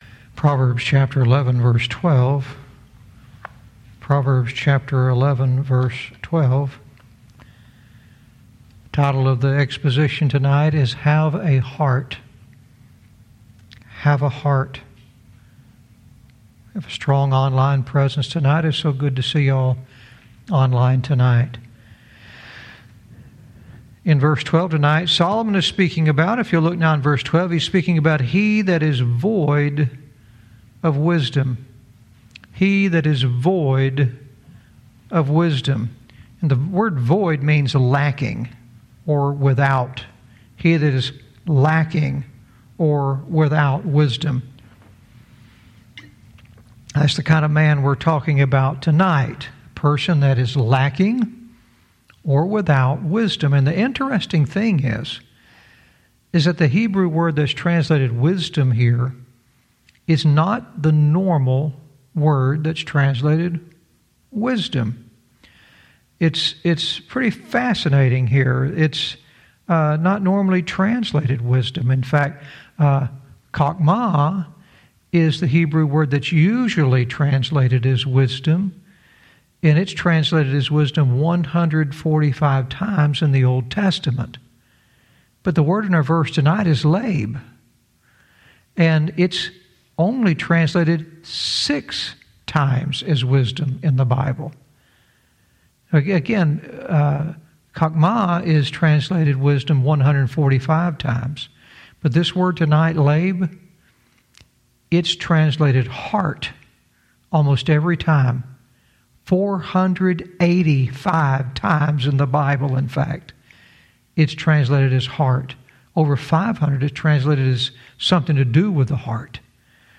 Verse by verse teaching - Proverbs 11:12 "Have a Heart"